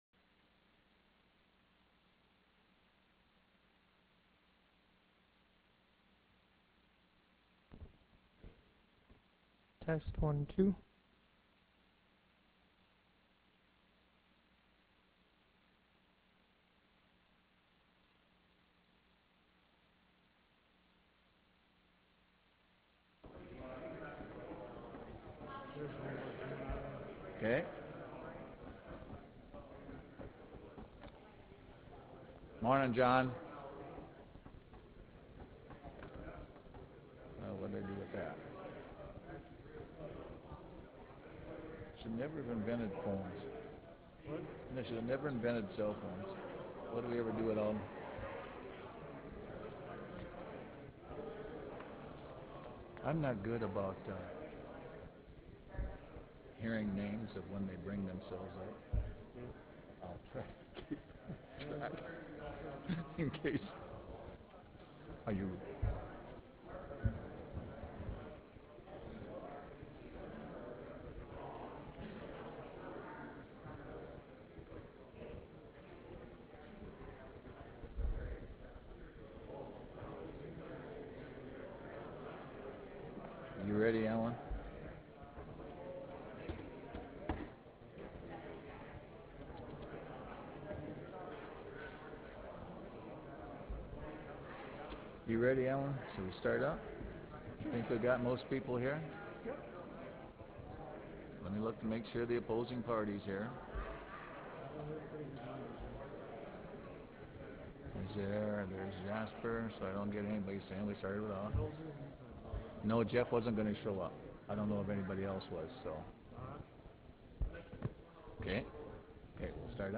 Senate Chamber State Capitol Bismarck, ND United States
Meeting Audio